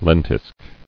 [len·tisk]